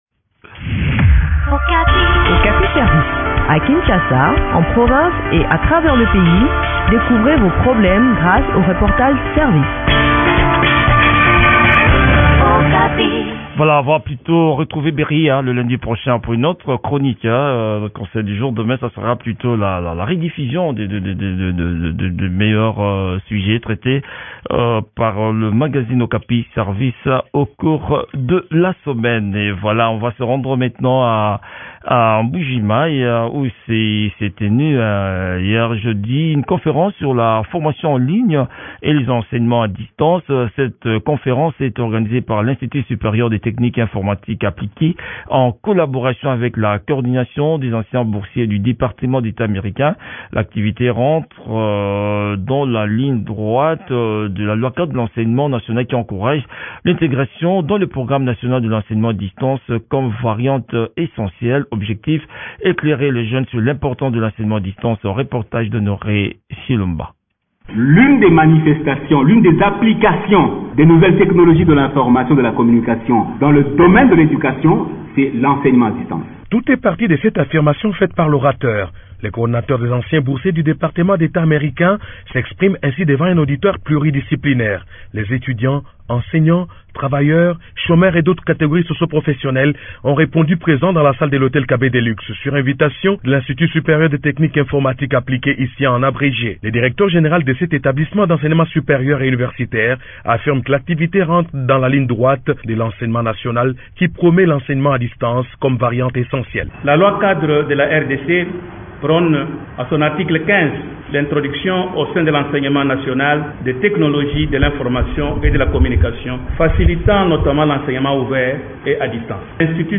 Le point sur l’organisation de cette conférence dans cet entretien